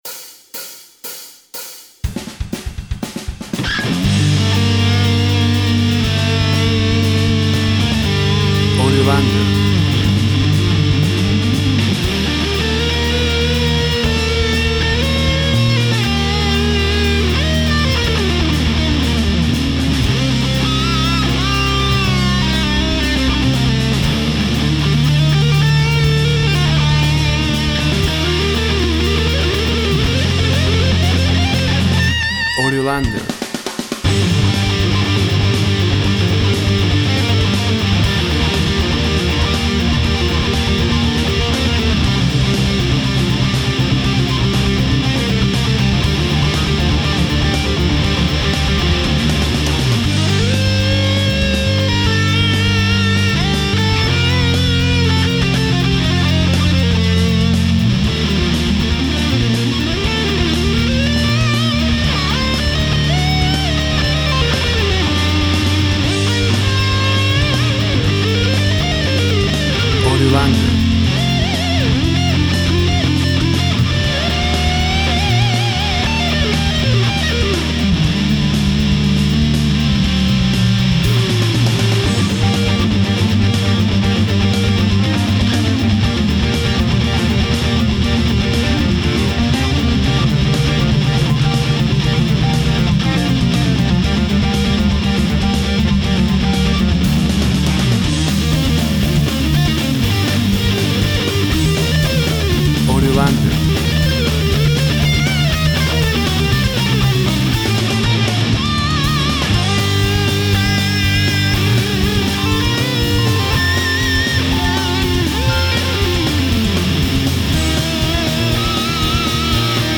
Instrumental rock fusion, Metal Rock.
Tempo (BPM) 110